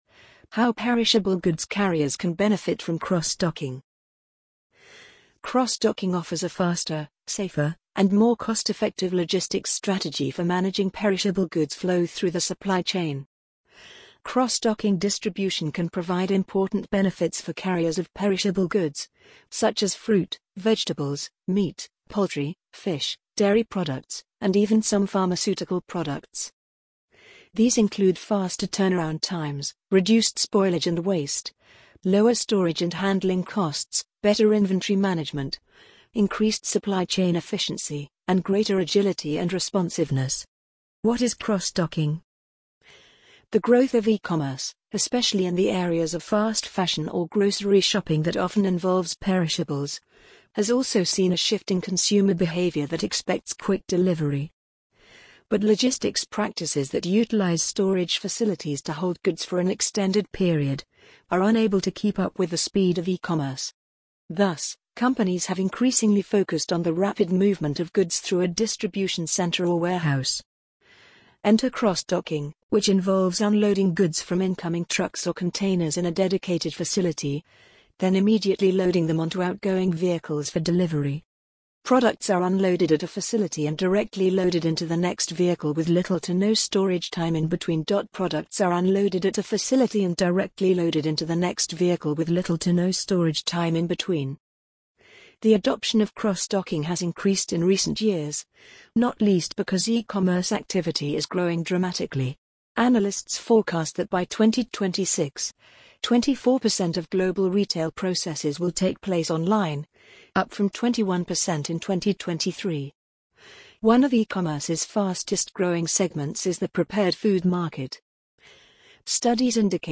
amazon_polly_45382.mp3